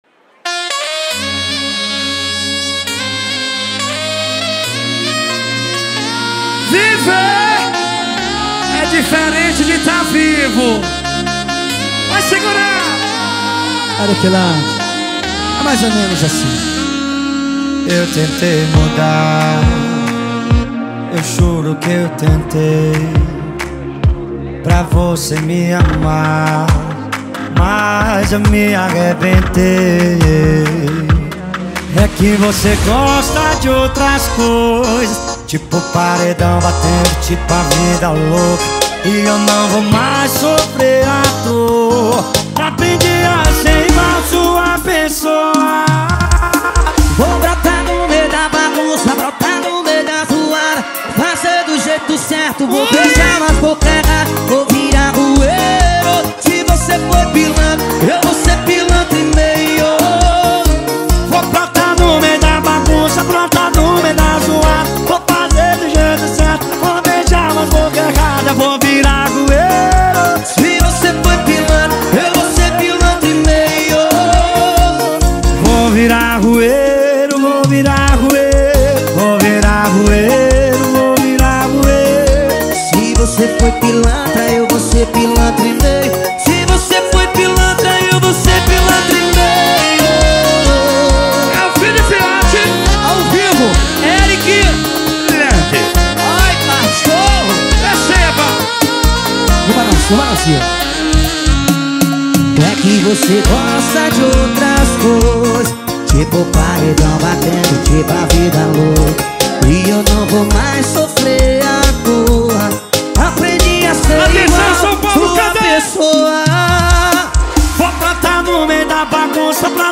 2024-12-29 20:11:49 Gênero: Sertanejo Views